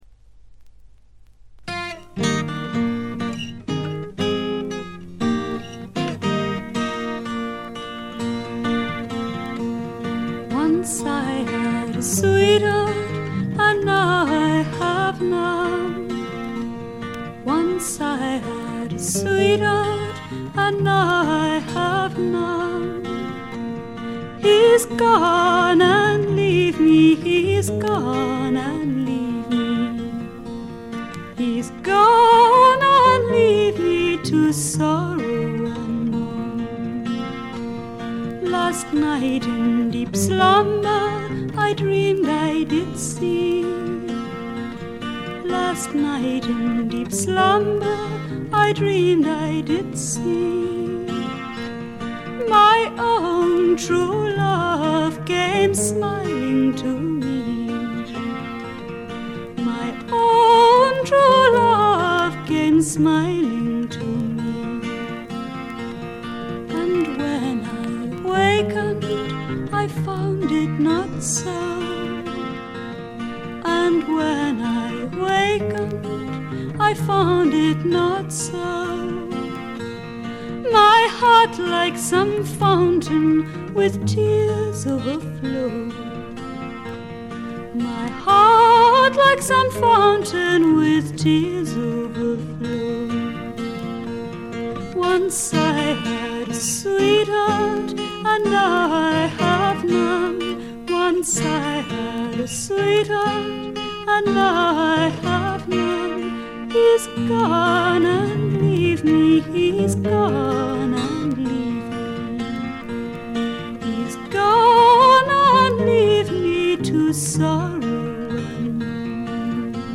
軽微なバックグラウンドノイズ程度。
英国フィメール・フォークの大名作でもあります。
内容はというとほとんどがトラディショナル・ソングで、シンプルなアレンジに乗せた初々しい少女の息遣いがたまらない逸品です。
モノラル盤です。
試聴曲は現品からの取り込み音源です。